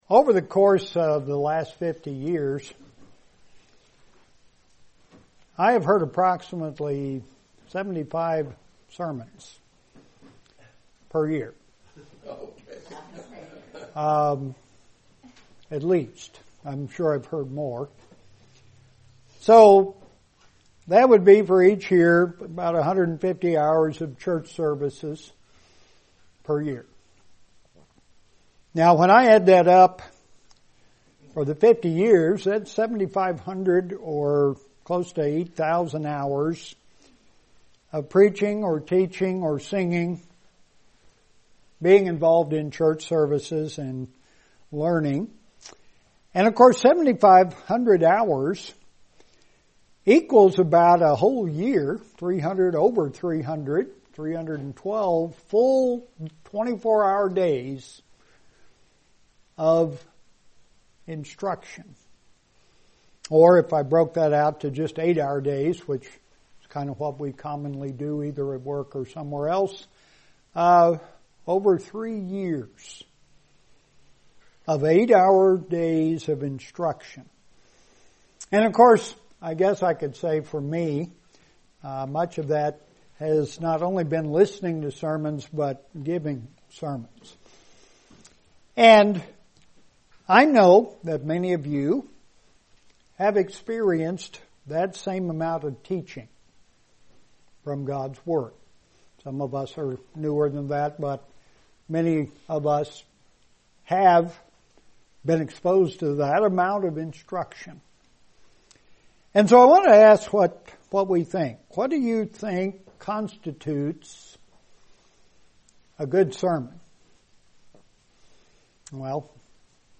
Three Great Sermons in the Book of Acts | United Church of God